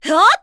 Laudia-Vox_Attack1_kr.wav